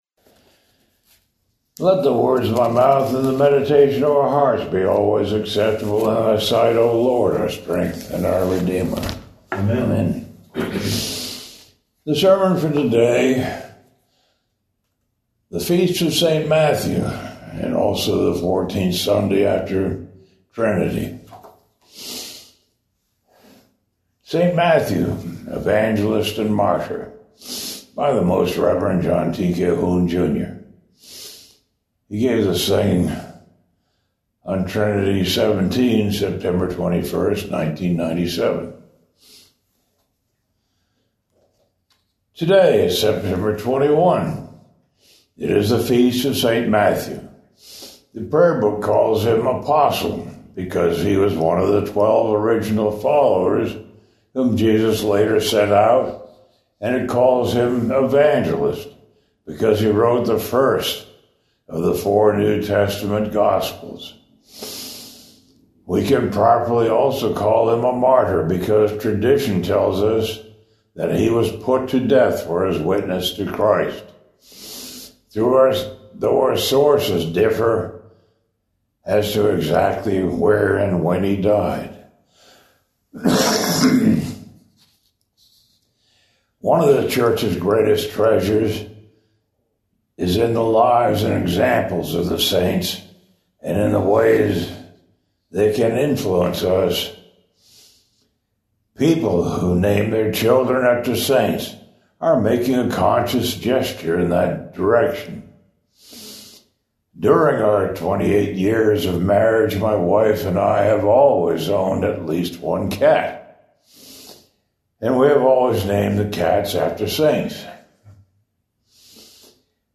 Morning Prayer - Lay Reader Service
Sermon Thought: